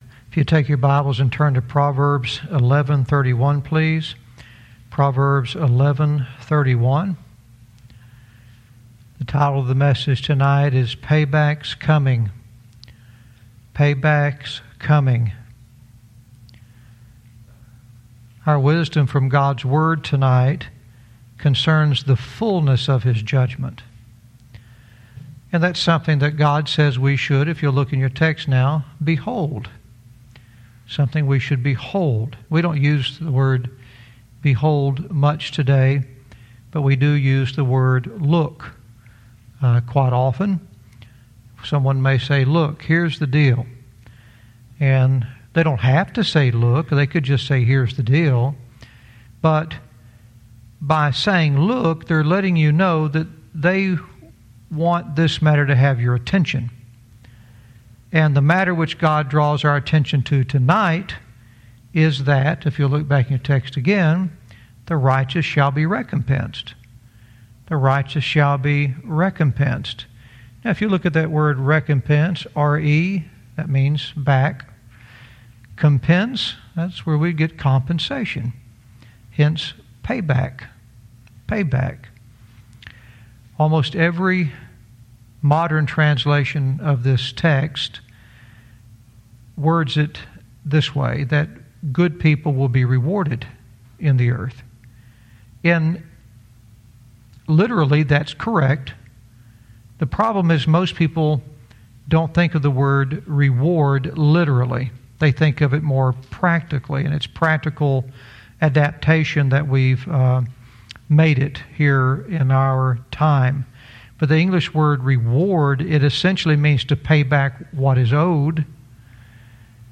Verse by verse teaching - Proverbs 11:31 "Pay Back's Coming"